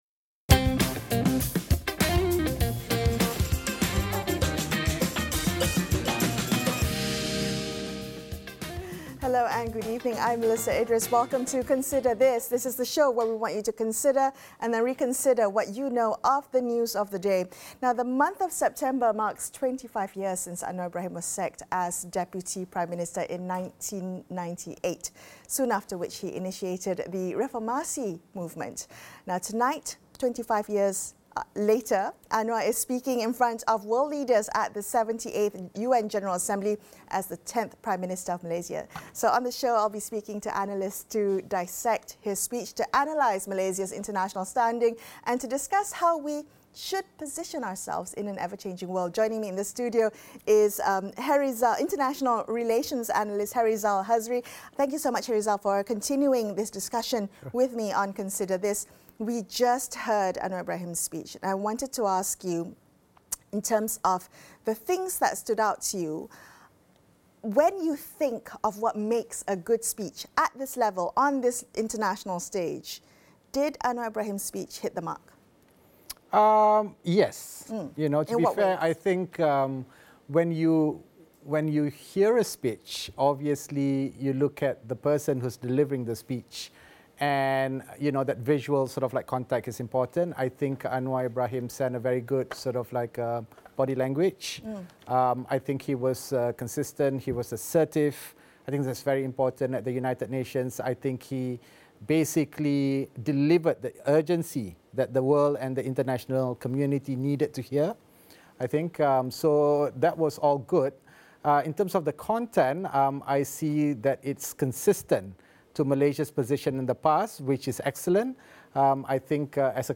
Ipoh Timor MP Howard Lee and International Relations analysts